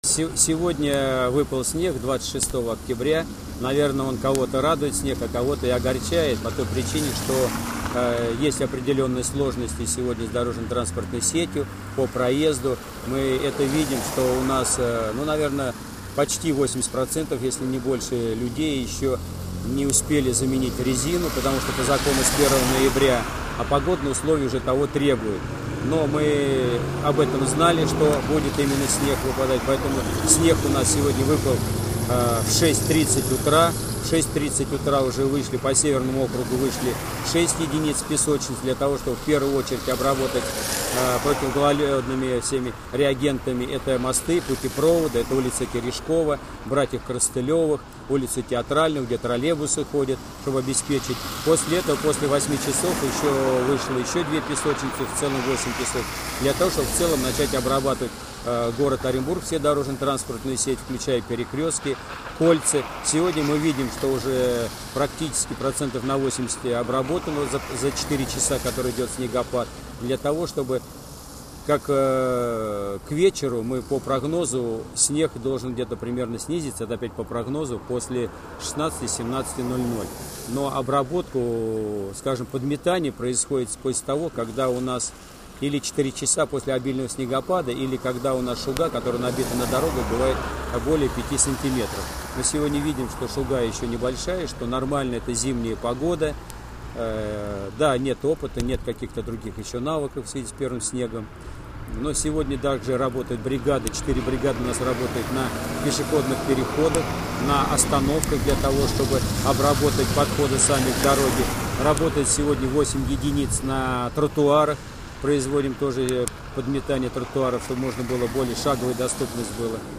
Глава Северного округа Сергей Чуфистов (о уборке снега):